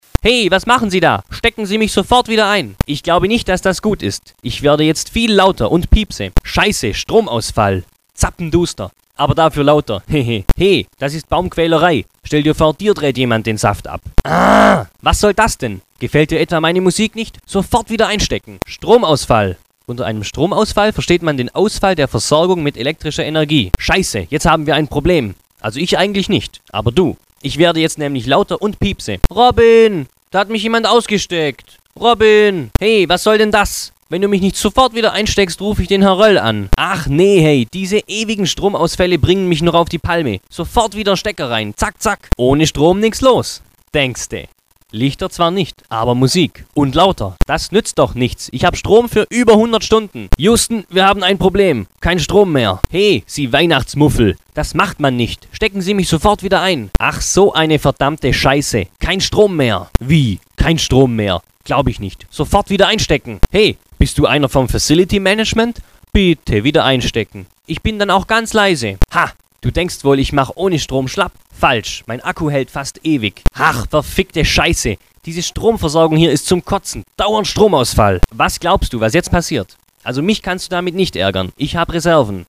Kategorie 1: Sprüche, die beim Ausstecken des Baums abgespielt werden: